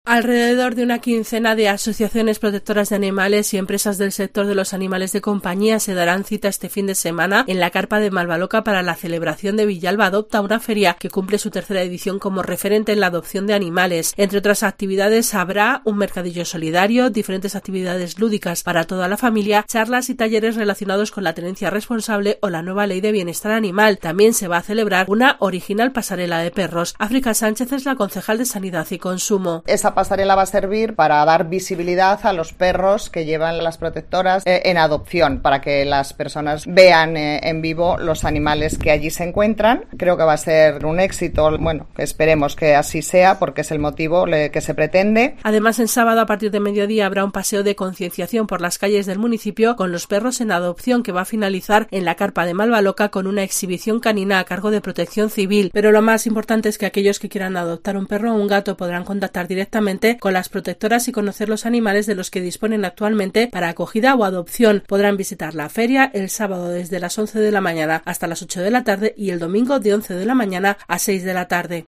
La edil de Sanidad y Consumo, África Sánchez, durante la presentación de la Feria